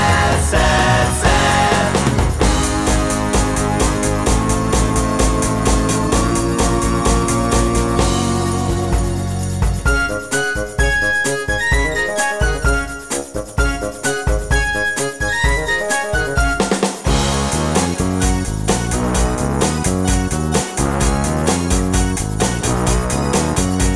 no Backing Vocals Soul / Motown 3:04 Buy £1.50